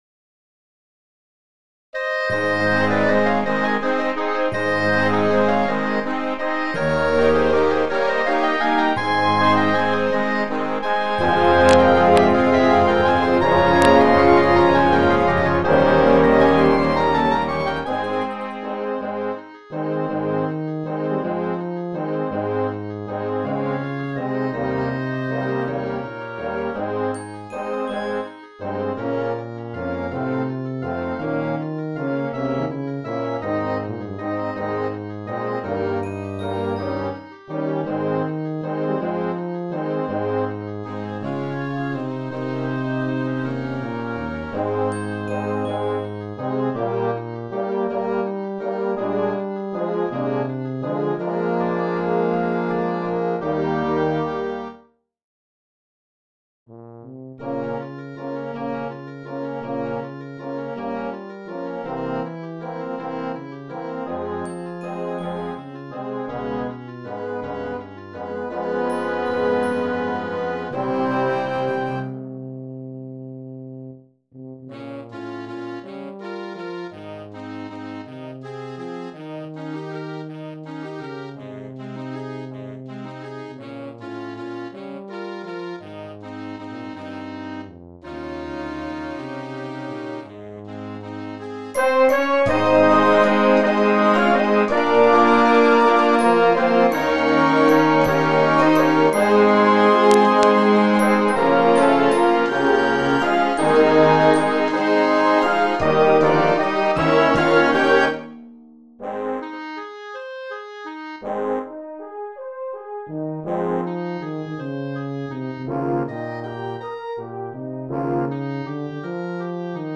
Gattung: Solo für Tuba und Blasorchester
Besetzung: Blasorchester